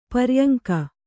♪ paryaŋka